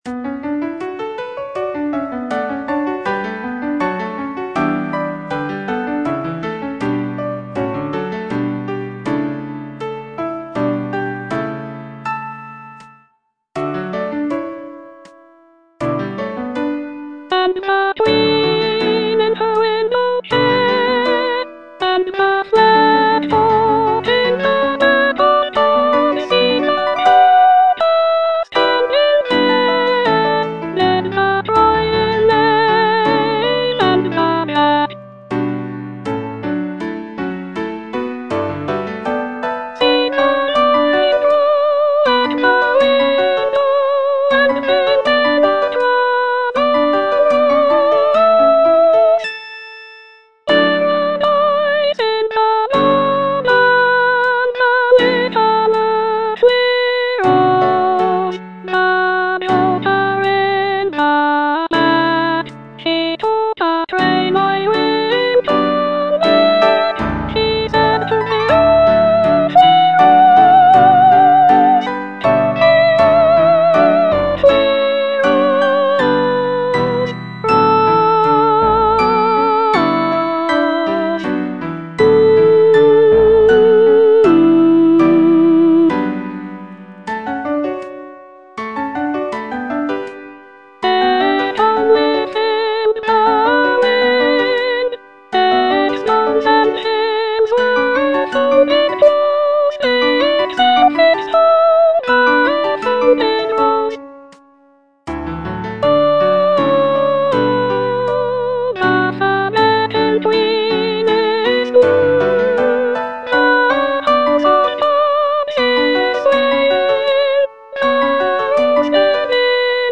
Soprano I (Voice with metronome)
is a choral work